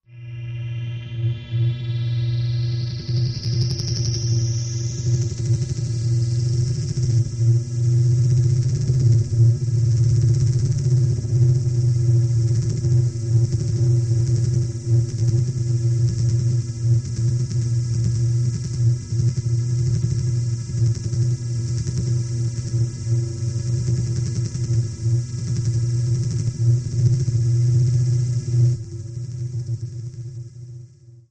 Simple Pulse , Machine, Space Electric, Pulse